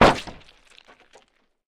splat.ogg